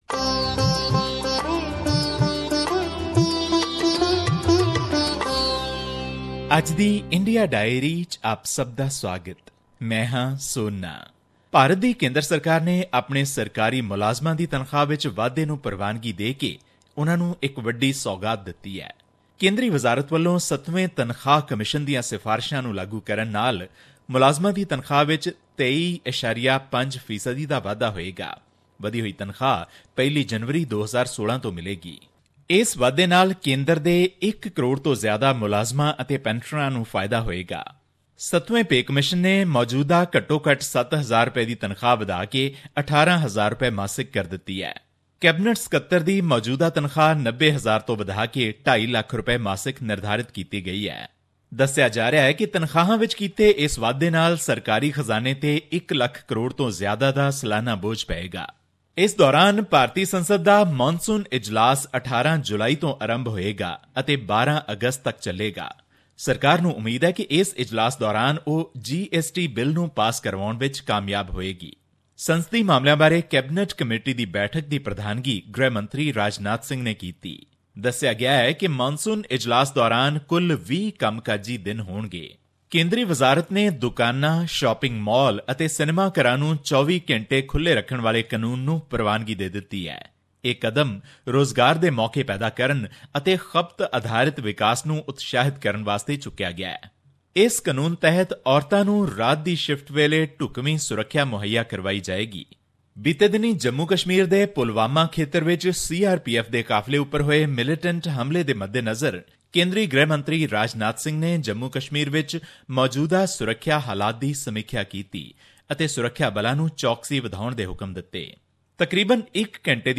His report was presented on SBS Punjabi program on Thursday, June 30, 2016, which touched upon issues of Punjabi and national significance in India. Here's the podcast in case you missed hearing it on the radio.